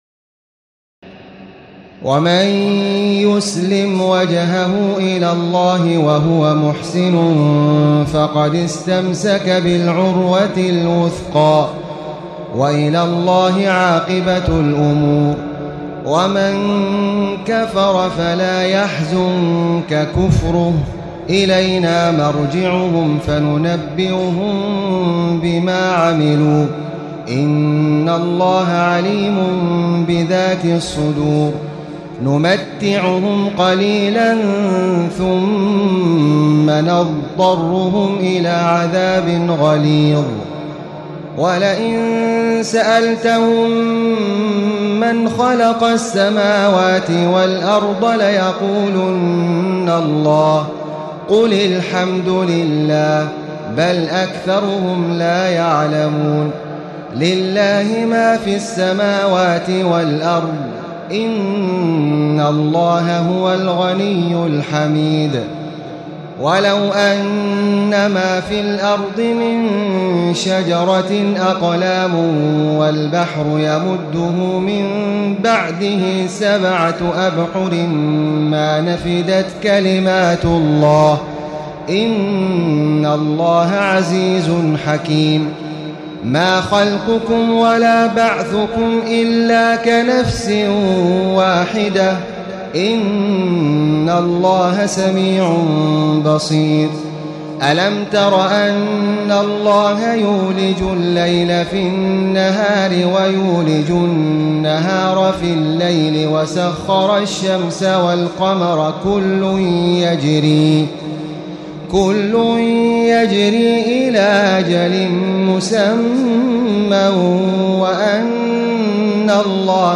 تراويح الليلة العشرون رمضان 1436هـ من سور لقمان (22-34) والسجدة و الأحزاب (1-34) Taraweeh 20 st night Ramadan 1436H from Surah Luqman and As-Sajda and Al-Ahzaab > تراويح الحرم المكي عام 1436 🕋 > التراويح - تلاوات الحرمين